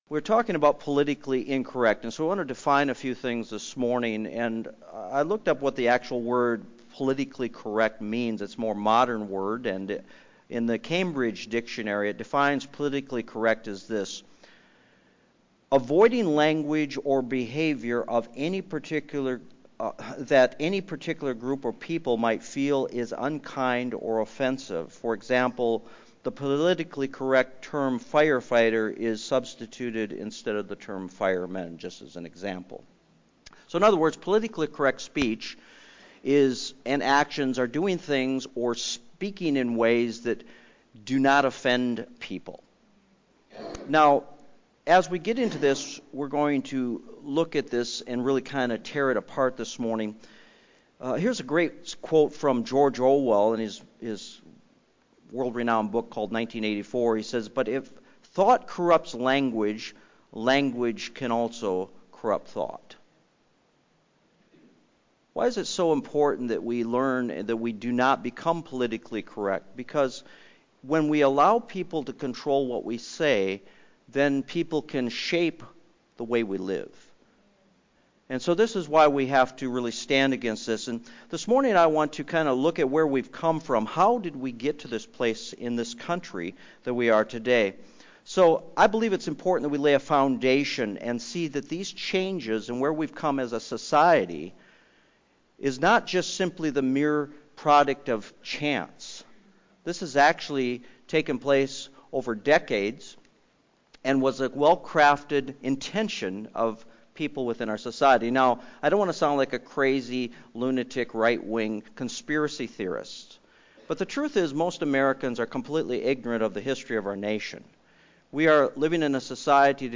Lesson 1 – How Did We Get Here – Outline